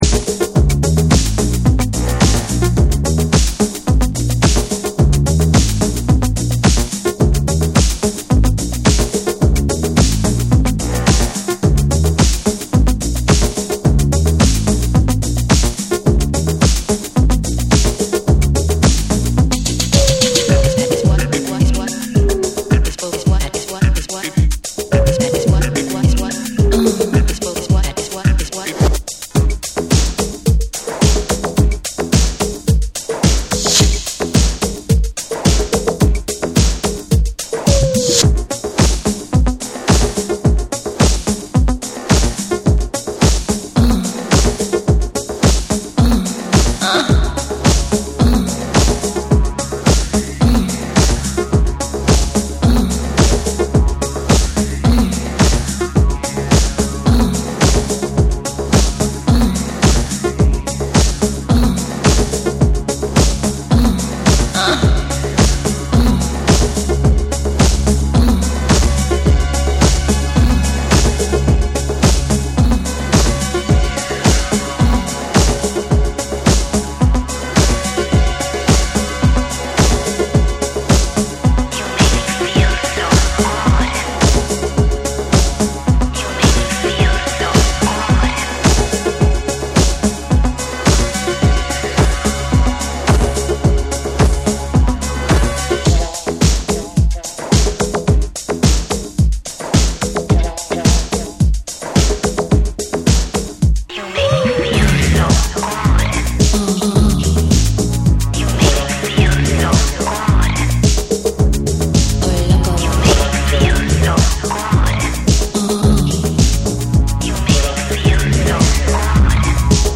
テクノとブレイクビーツが交差する躍動感のあるビートが、心地よいアンビエント空間に溶け込むエレクトリックでダビーな大名曲！
BREAKBEATS